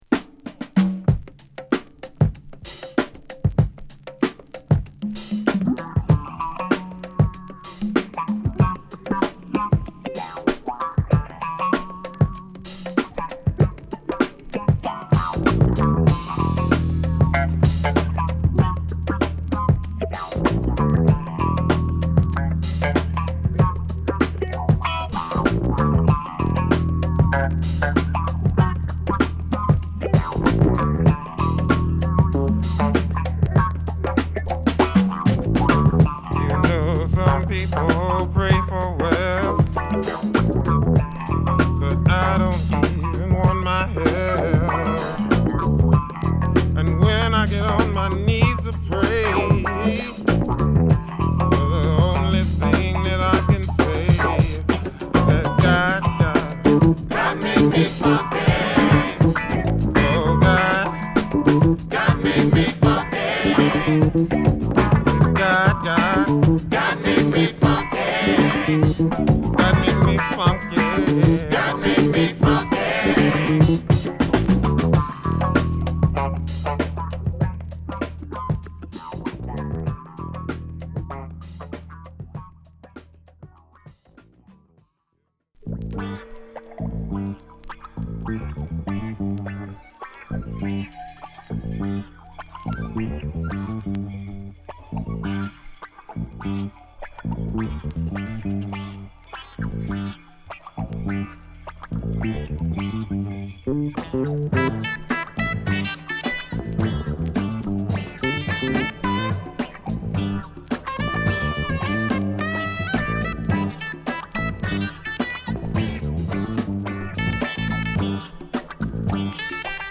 ヘヴィーなベースとファンキーなドラムにモーダルなサックスが絡むフリーキーなジャズファンク